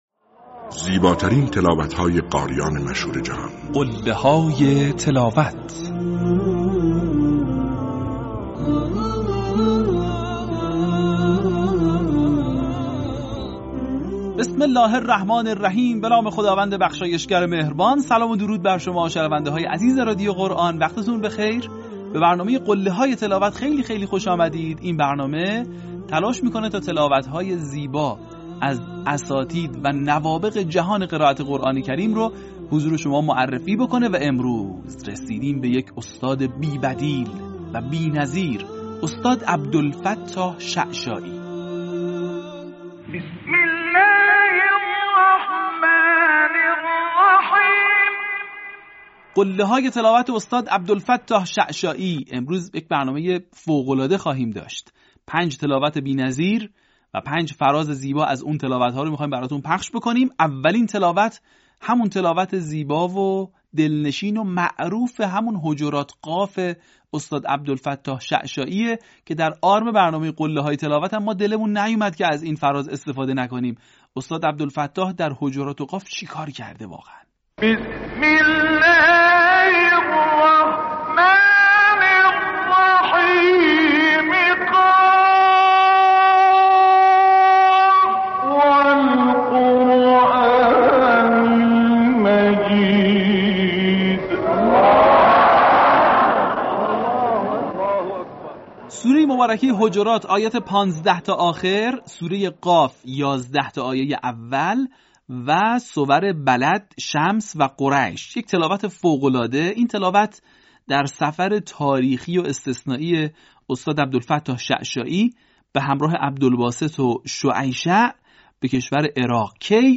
برچسب ها: عبدالفتاح شعشاعی ، قله های تلاوت ، تلاوت قرآن ، تلاوت به یاد ماندنی